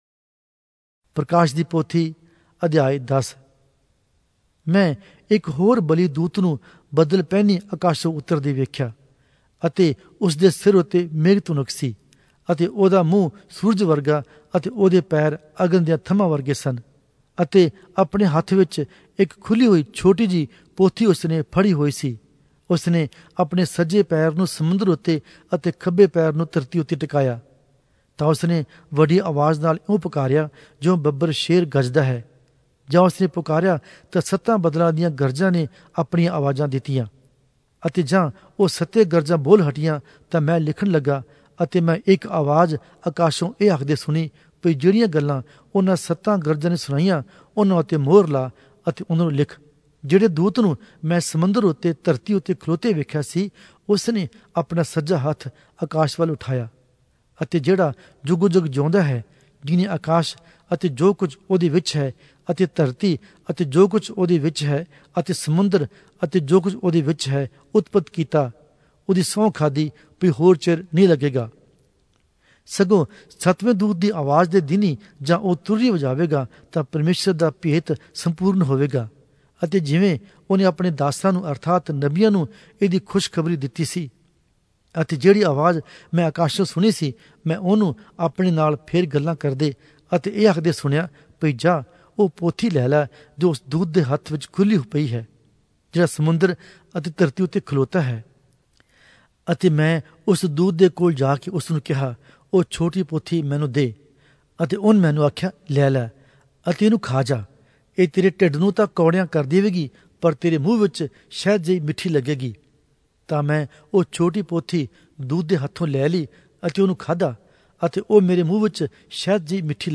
Oriya Audio Bible - Revelation 21 in Ervte bible version